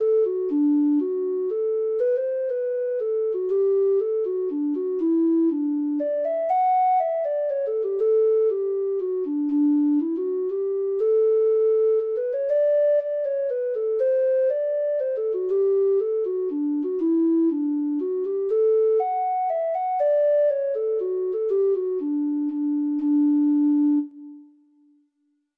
Treble Clef Instrument Traditional Treble Clef Instrument Traditional Treble Clef Instrument Free Sheet Music Pretty Peggy (Irish Folk Song) (Ireland)
Reels
Irish